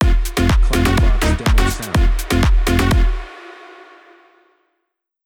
“Dark Delirium” Clamor Sound Effect
Can also be used as a car sound and works as a Tesla LockChime sound for the Boombox.